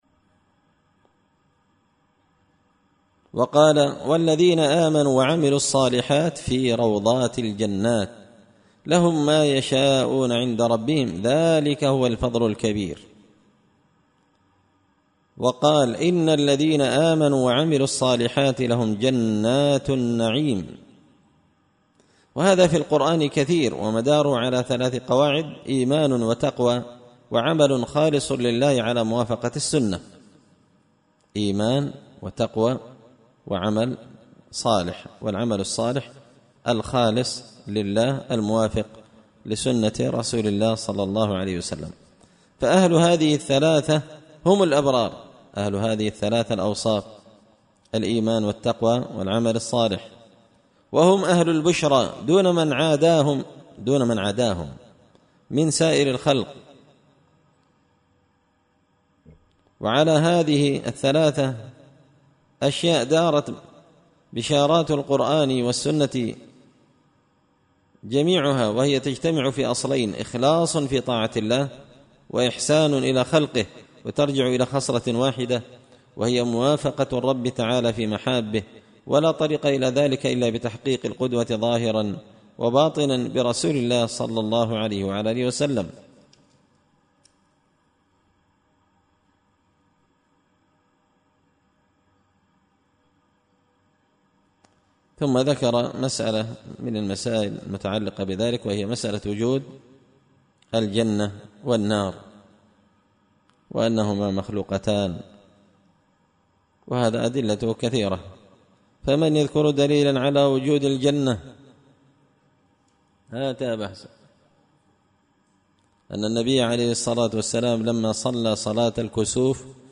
شرح لامية شيخ الإسلام ابن تيمية رحمه الله _الدرس 43